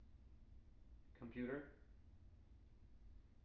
wake-word
tng-computer-109.wav